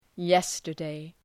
Προφορά
{‘jestərdı, ‘jestərdeı}